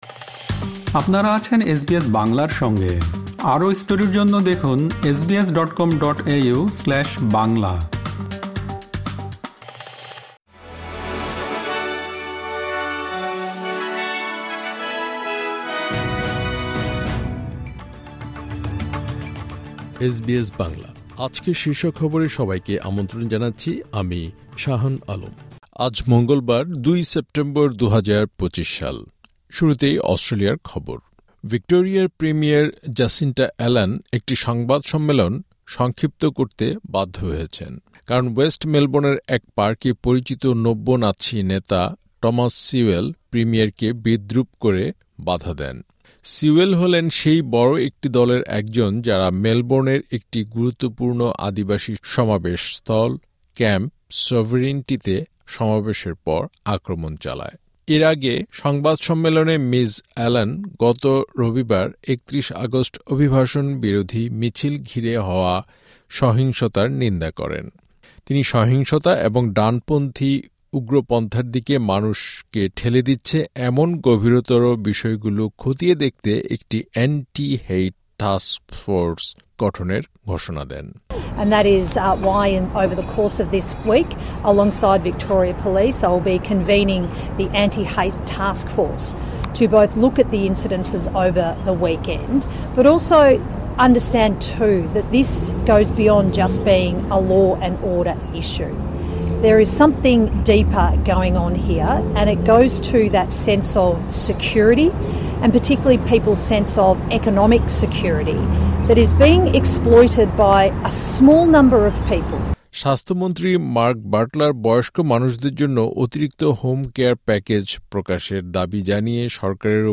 আজকের শীর্ষ খবর